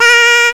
Index of /m8-backup/M8/Samples/Fairlight CMI/IIX/CHORAL
LA.WAV